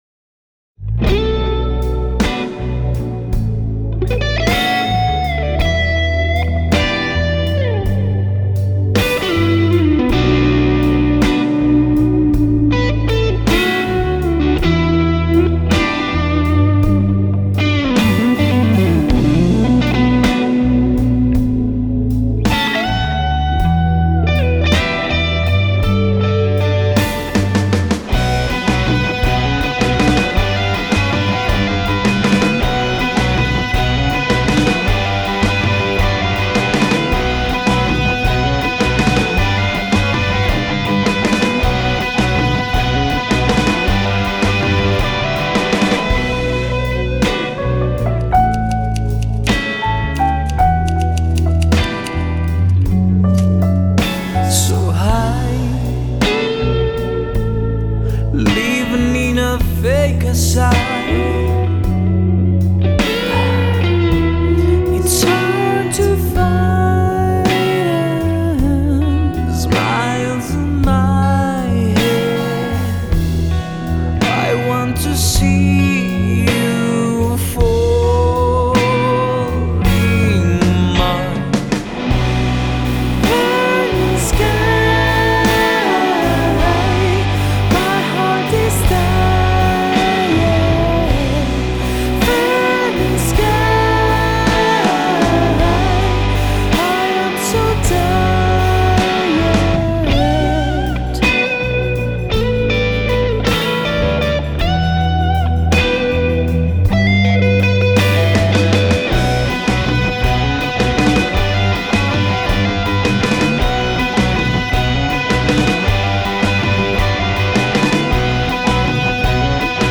Repositorio Institucional Universidad de Cuenca: Grabación y producción de dos temas inéditos en género rock fusión realizados en un “home studio”
Rock fusión
Home studio